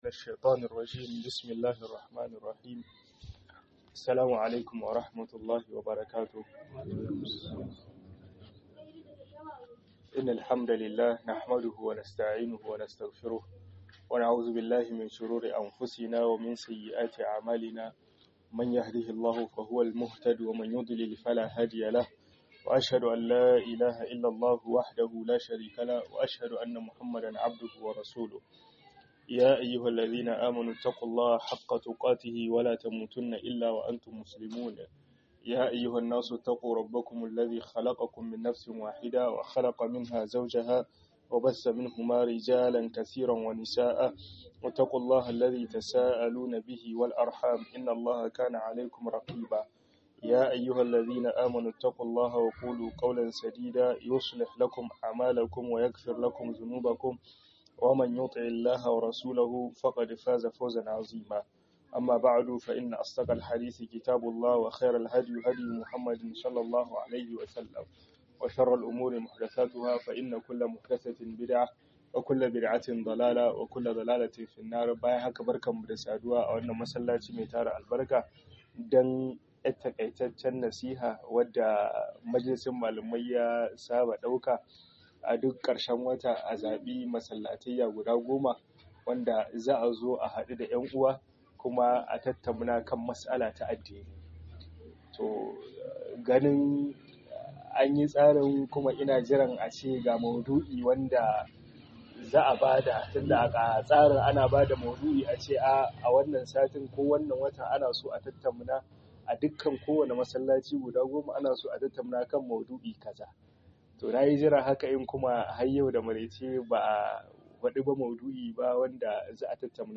ib'tila'i 2 - HUDUBA